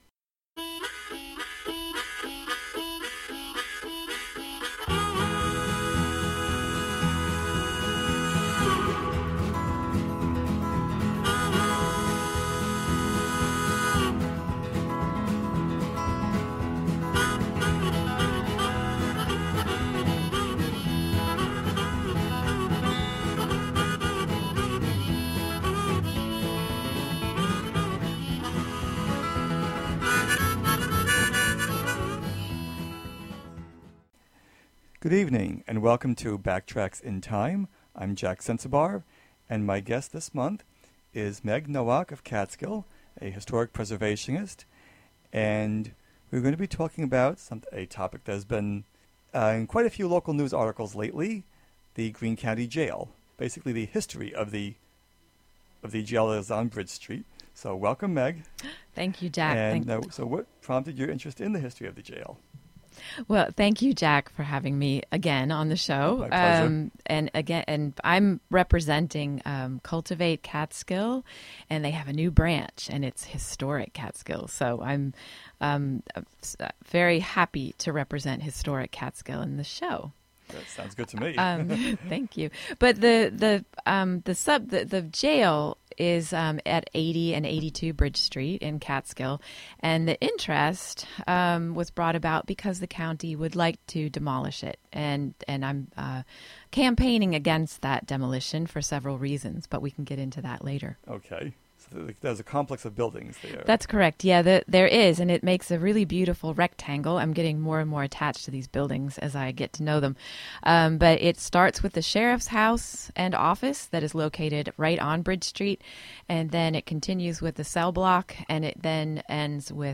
"Backtracks in Time" features interviews with local historians, longtime residents with stories to tell, and archival recorded material.